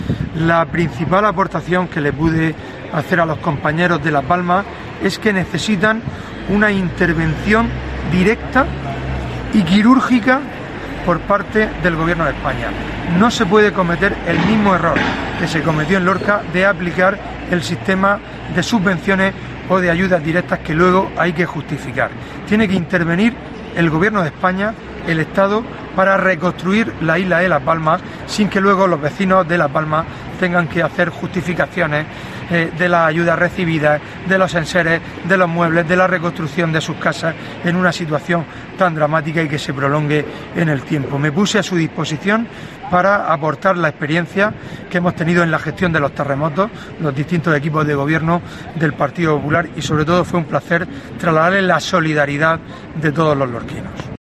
Fulgencio Gil, portavoz del PP